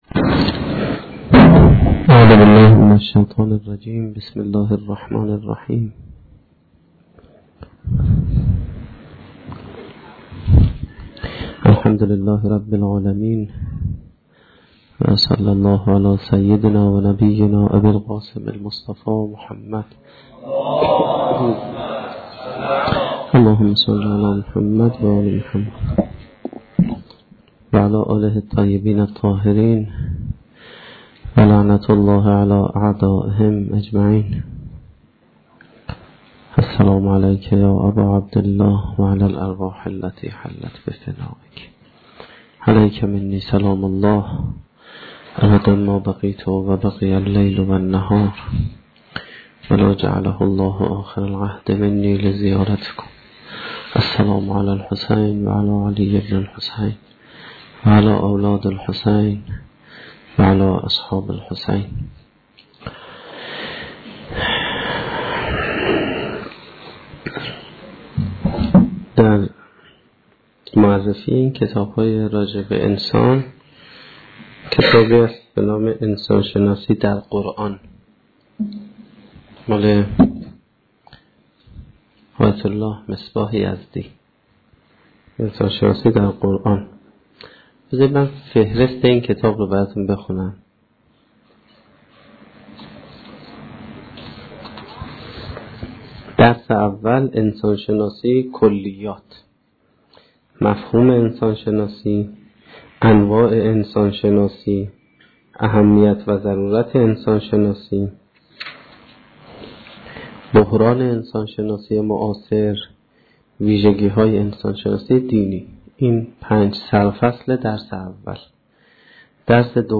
سخنرانی شب 22 محرم1435-1392